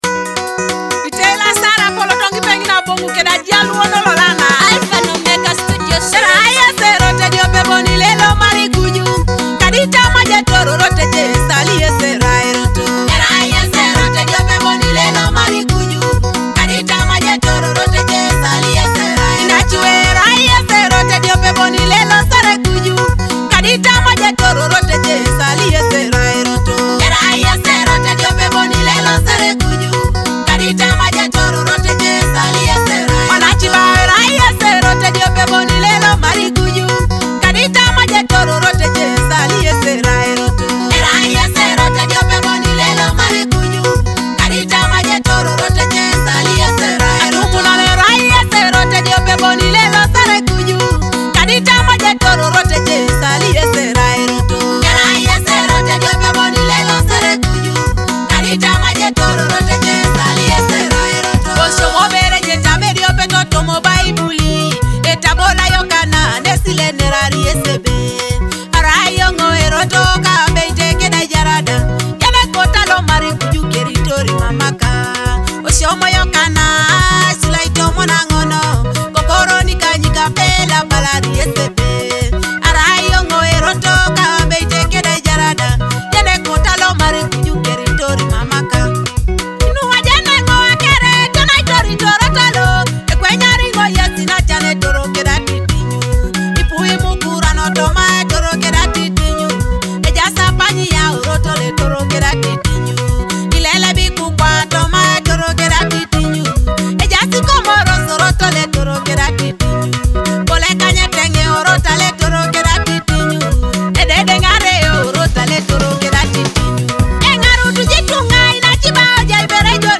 a soulful Teso gospel song celebrating Jesus as the way.